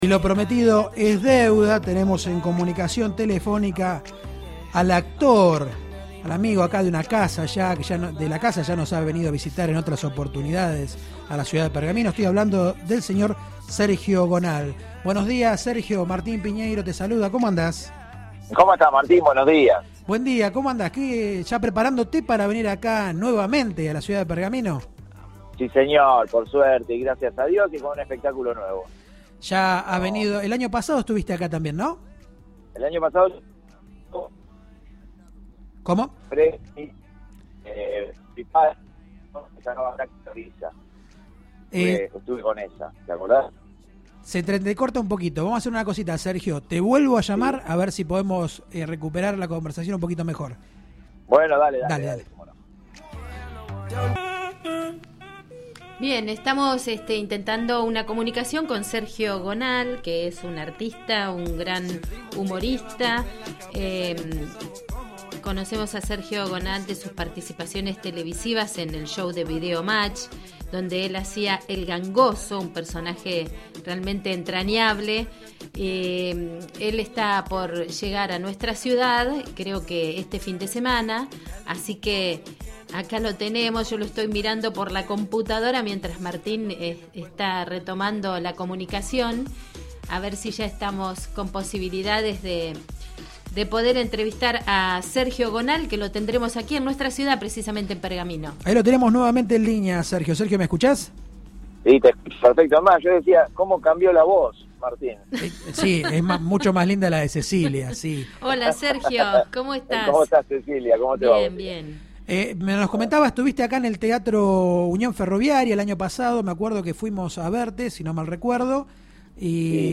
Locales-Sergio Gonal llega a Pergamino y pasó por Radio Verdad